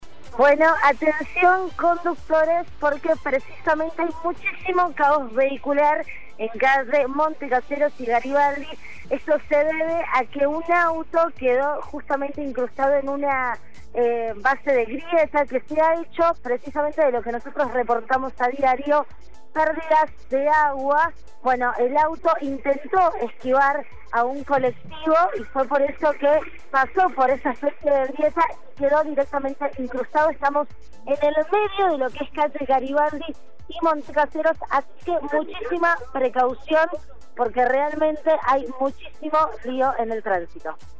LVDiez - Radio de Cuyo - Móvil de LVDiez- auto atrapado en grieta por pérdida de agua en Ciudad